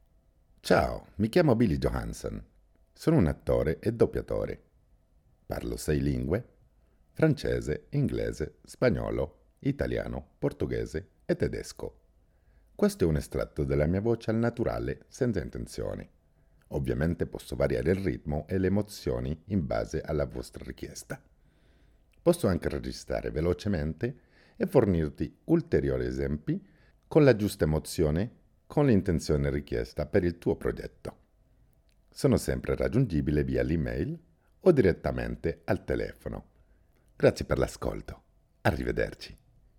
Voix off
40 - 80 ans - Baryton-basse